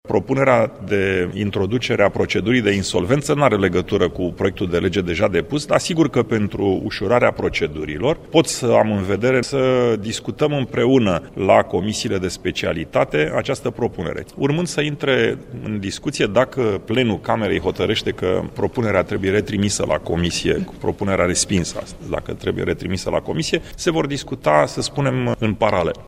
Tăriceanu a precizat că va susţine propunerile sale, ca amendamente, la Legea PSD, dacă proiectul social-democraţilor va fi retrimis la Comisia de specialitate din Camera Deputaţilor: